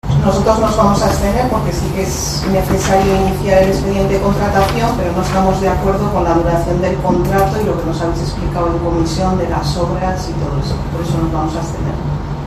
La edil no adscrita, Laura Vidaurre, justificó su abstención en la duración del contrato y las obras.
VIDAURRE-PLENO_.mp3